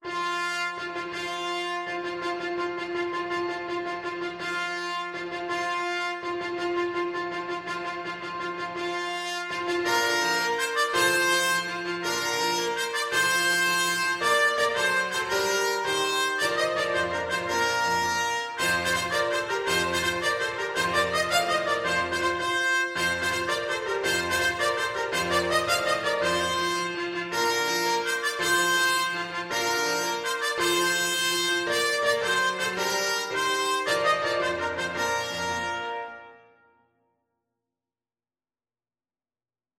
With energy .=c.110
6/8 (View more 6/8 Music)
F5-F6
Classical (View more Classical Trumpet Music)